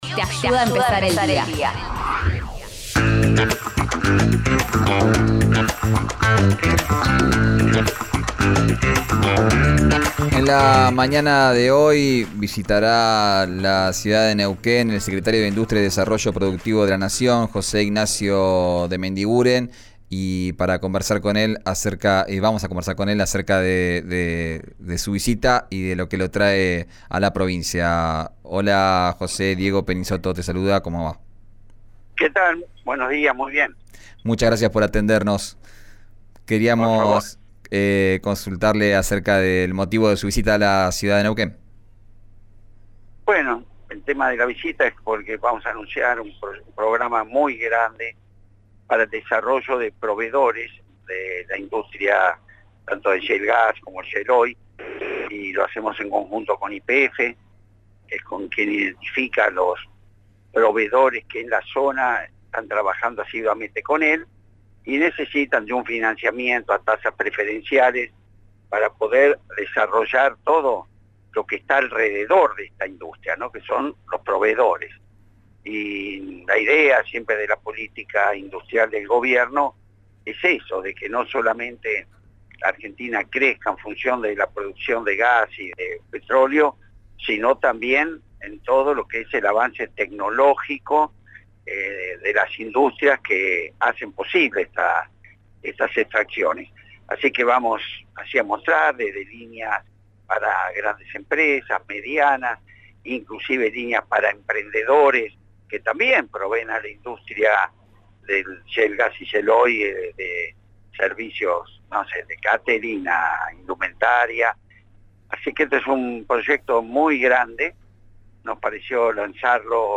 En diálogo con el programa «Arranquemos» por RÍO NEGRO RADIO antes de embarcar rumbo a Neuquén, De Mendiguren dio detalles respecto a la visita a la provincia y brindó sus impresiones en relación al momento que atraviesa la actividad industrial y el tipo de cambio.
Escuchá a José Ignacio de Mendiguren en «Arranquemos» por RÍO NEGRO RADIO: